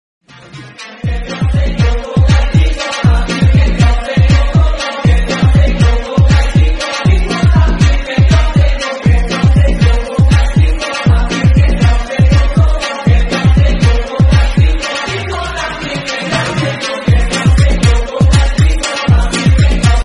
karnaval sound horeg ngeneiki ta✋🏻